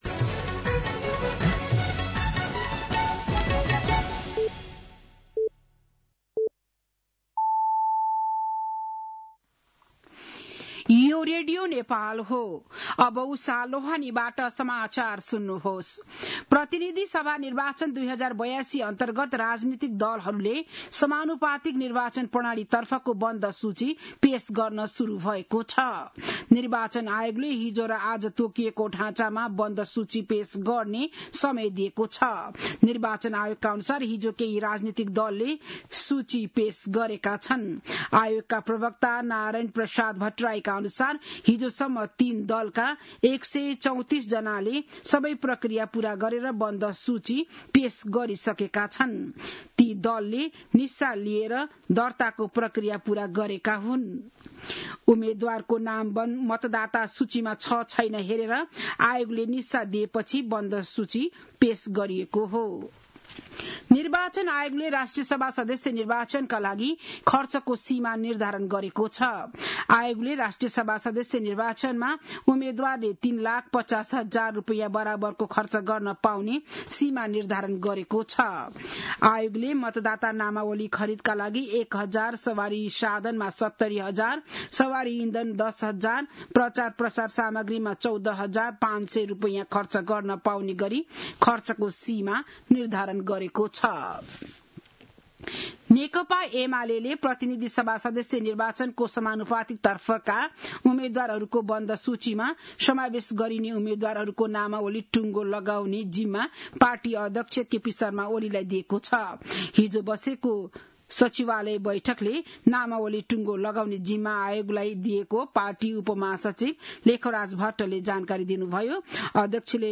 An online outlet of Nepal's national radio broadcaster
बिहान ११ बजेको नेपाली समाचार : १४ पुष , २०८२